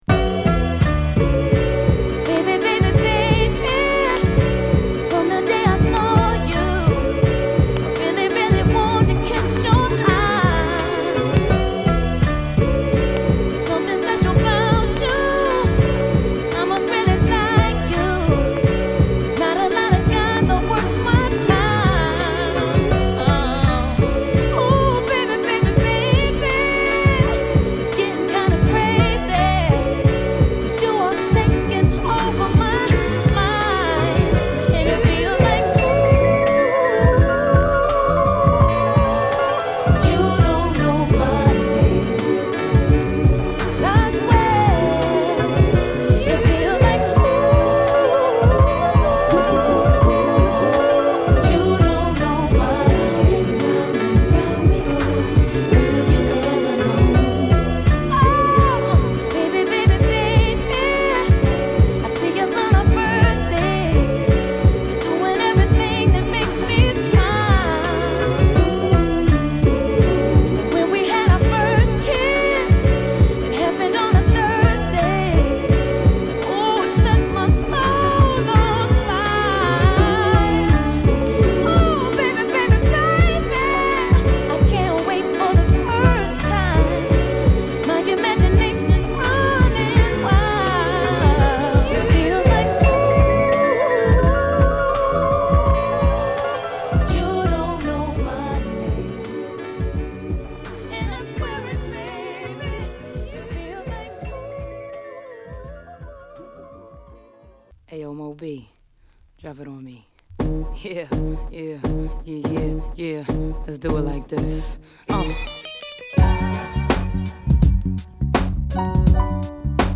＊B1頭に傷有り。プチノイズ出ます。